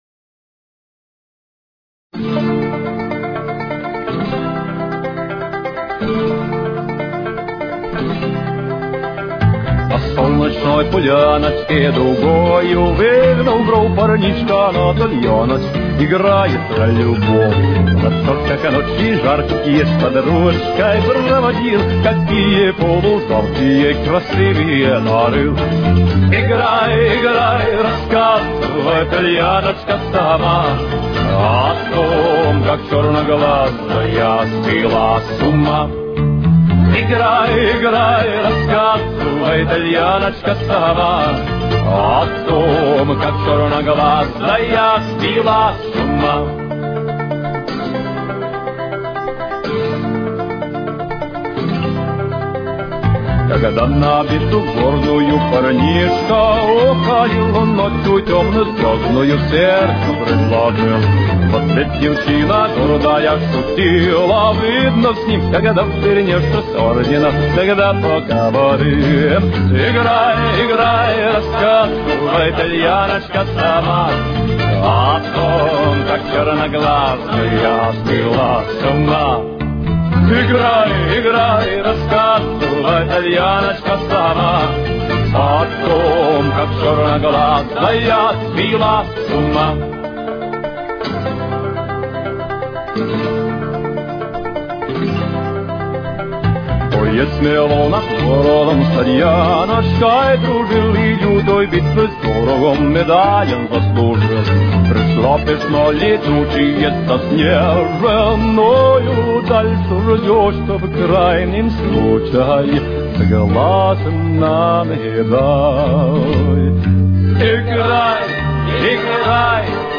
Ре минор. Темп: 120.